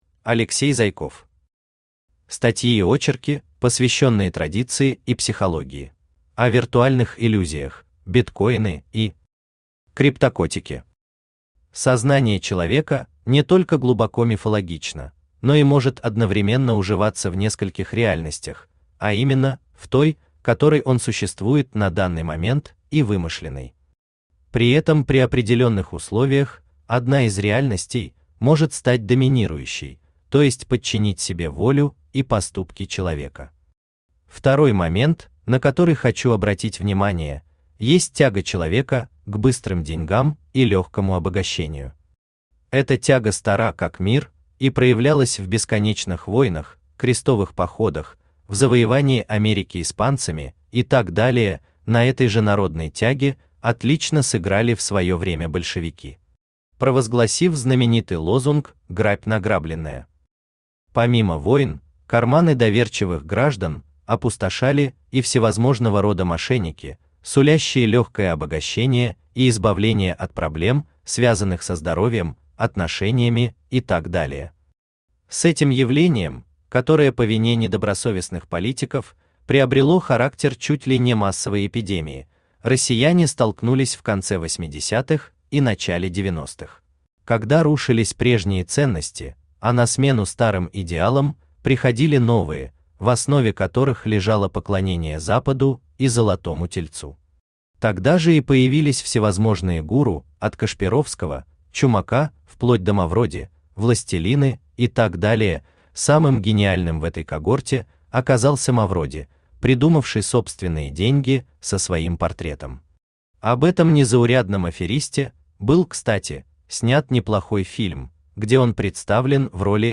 Аудиокнига Статьи и очерки, посвященные Традиции и психологии | Библиотека аудиокниг